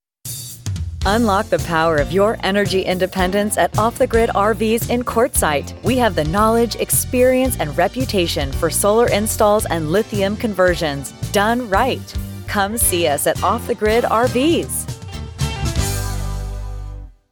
If you're looking for an English American female with an engaging, friendly, warm voice to grab and keep your listener's attention, I'm your gal.
Off the Grid radio ad